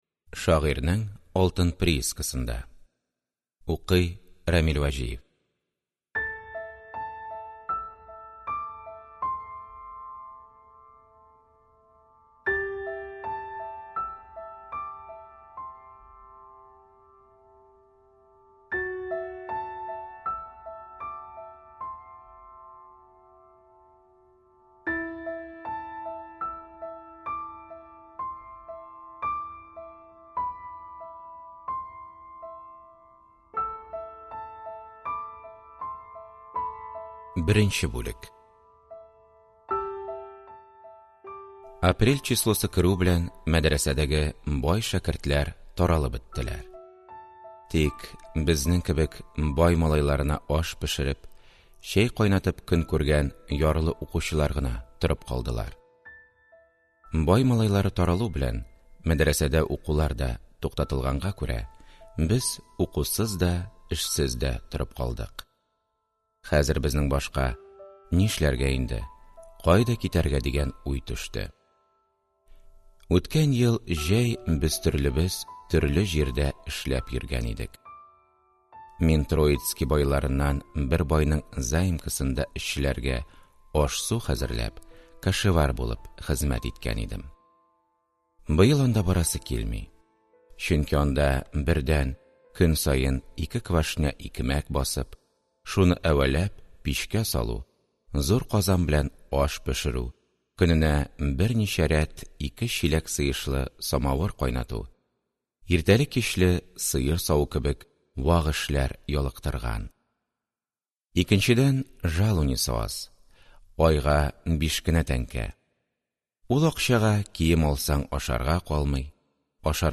Аудиокнига Шагыйрьнең алтын приискысында | Библиотека аудиокниг